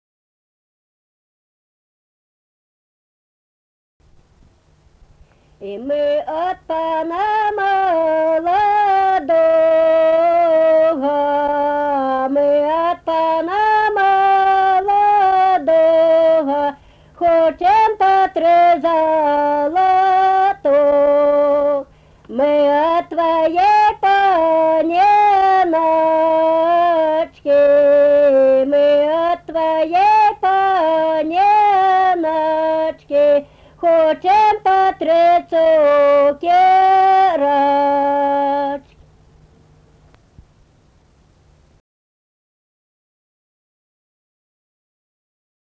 Atlikimo pubūdis vokalinis
Baltarusiška daina